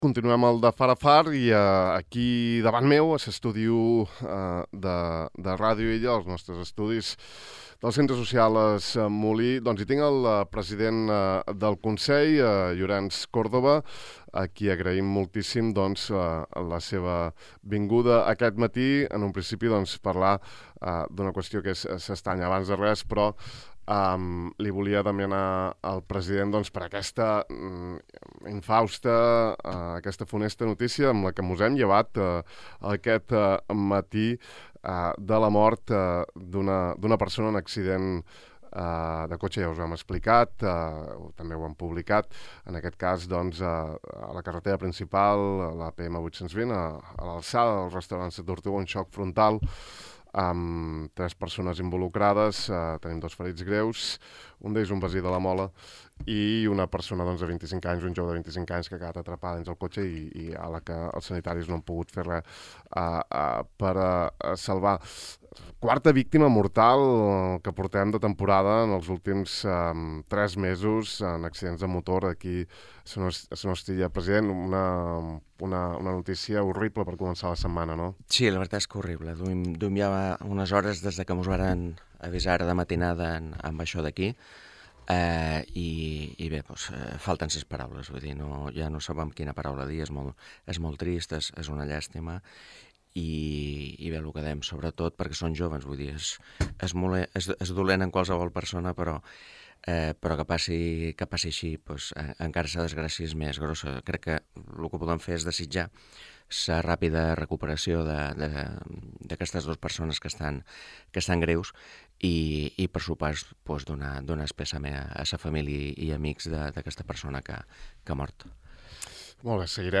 En una llarga entrevista a l’estudi 1 de Ràdio Illa